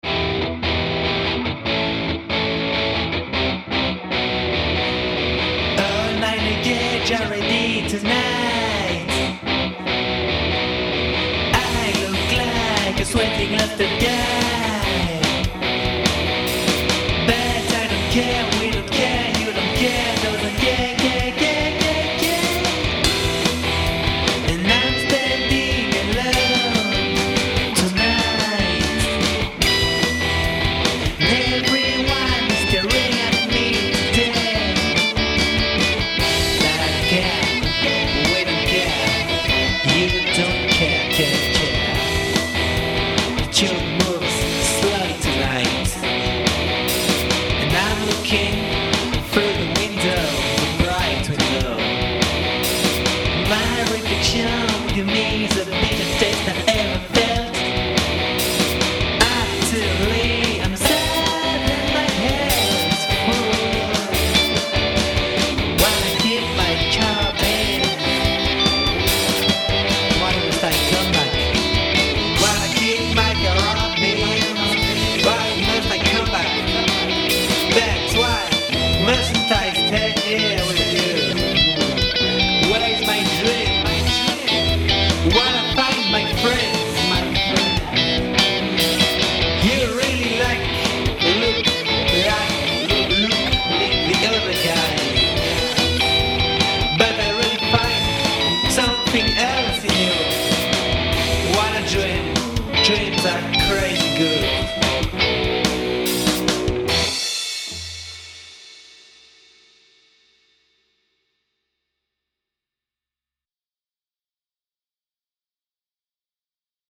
Je vous passe le lien vers mon petit dernier qui est à mon goût trop pop mais bon, c'était le délire de la chanson au début donc...
Tu composes de vraies chansons, ya de jolies trouvailles et ton timbre de voix est assez convainquant.
Par contre tu devrais y aller mollo sur la reverb wink.gif
ouais c'est beaucoup mieux! super son sur la voix
Je pense que je vais effectivement un peu diminuer la reverb sur guitares et voix, mais pas trop car c'est le style que je voulais donner à la chanson au début quand même !!!